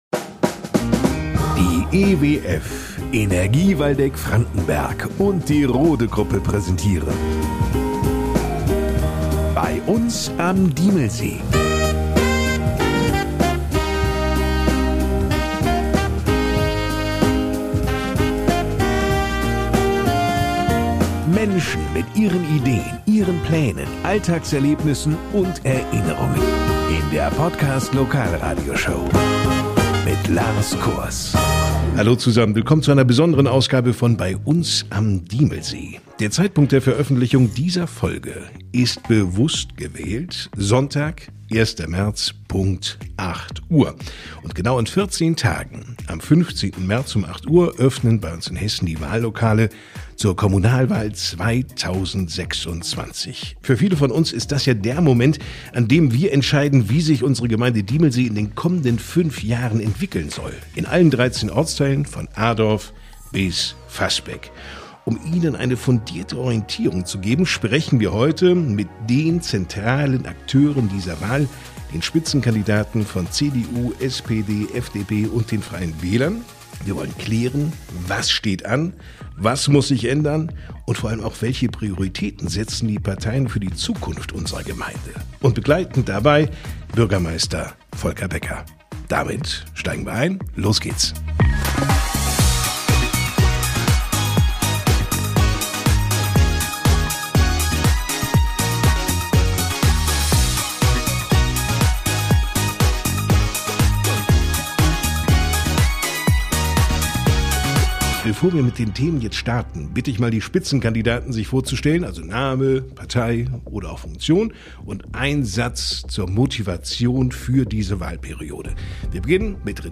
Bürgermeister Volker Becker und Vertreter aller Fraktionen diskutieren offen ihre Ansichten und geben Orientierung für alle, die Diemelsee mitgestalten wollen.